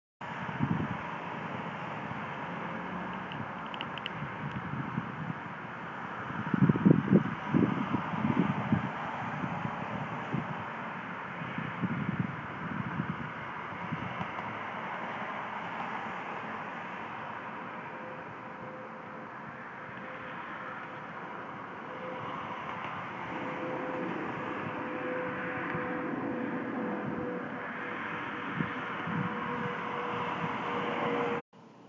Field Recording #6 – Walking Home
Sounds heard: my footsteps, a wheelbarrow, and people walking behind and around me.
Farthest: people talking behind me